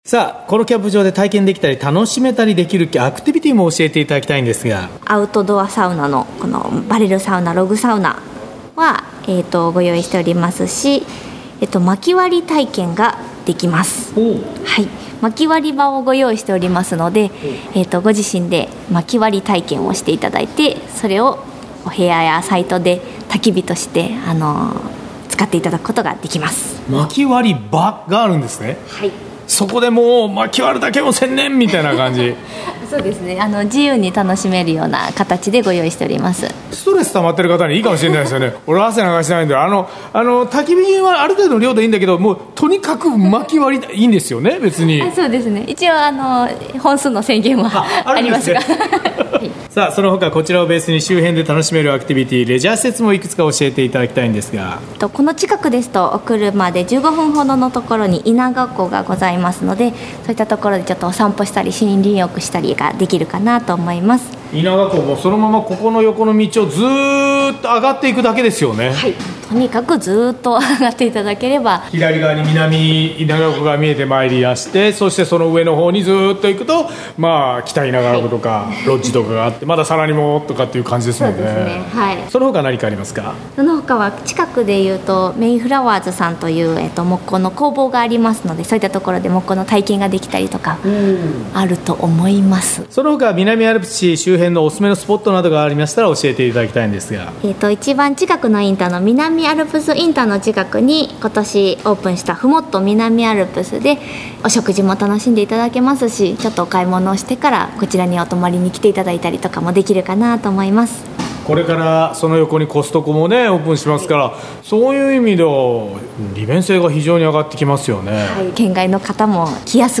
毎週土曜午前11時からの生放送。